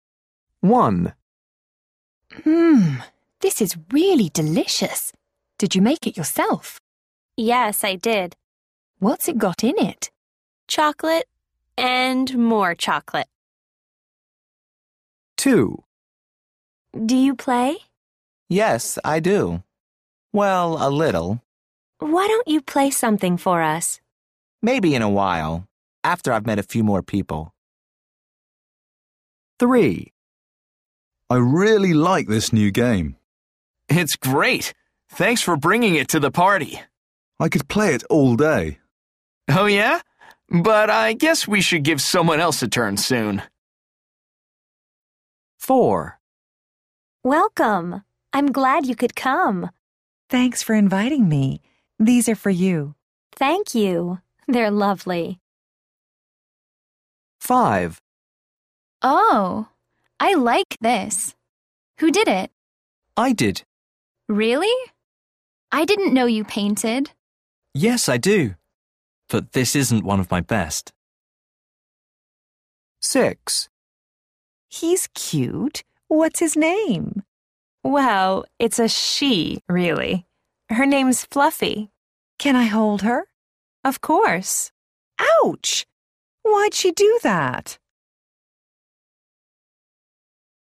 A. People are talking at parties. Listen and number the pictures.